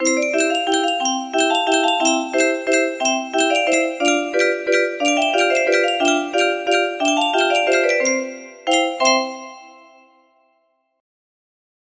ag music box melody